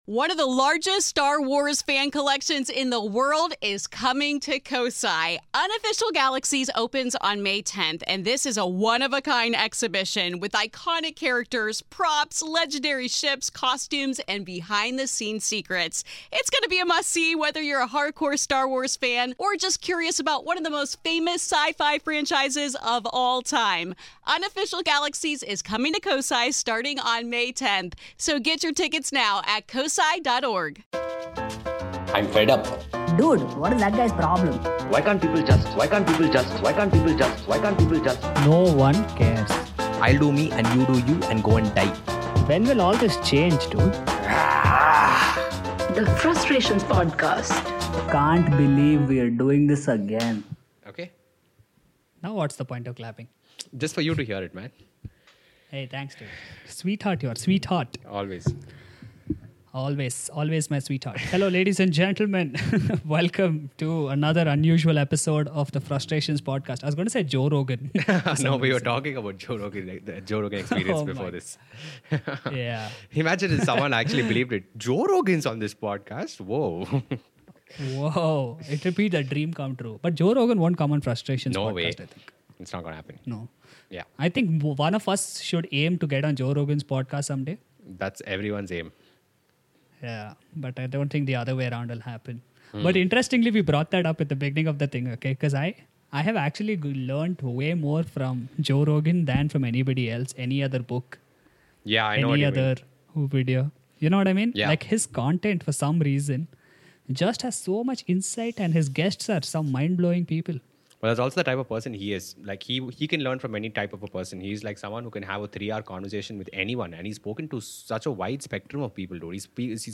The comedians take on many pressing issues in this episode.